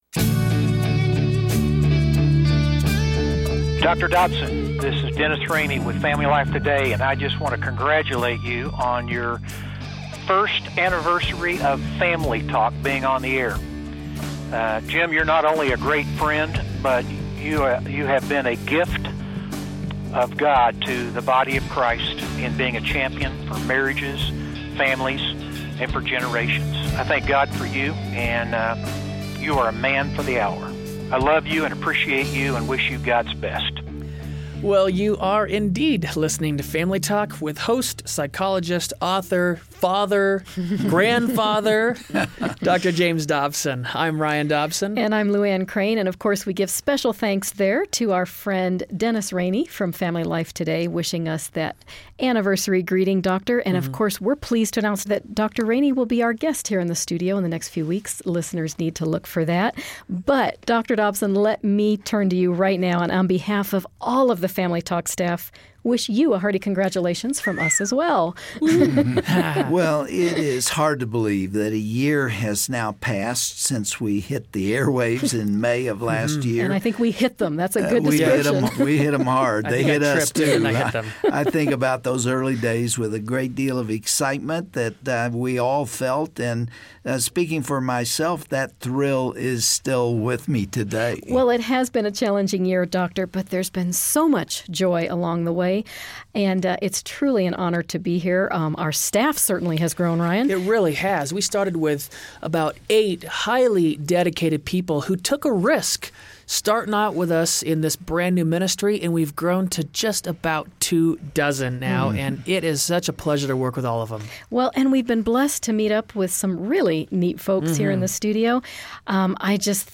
Celebrate with us as we hear from friends who have called in to share stories and their response to our program guests' advice from the past year!